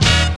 JAZZ STAB 7.wav